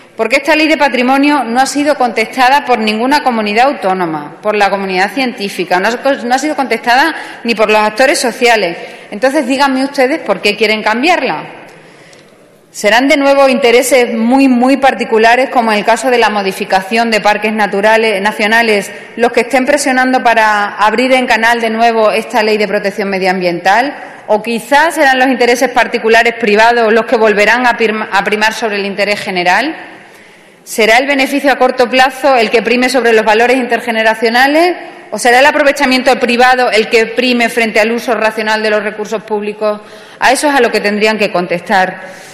Fragmento de la intervención de Pilar Lucio en el debate en el Congreso de una propuesta del PP para modificar la Ley de Patrimonio Natural y Biodiversidad 28/10/2014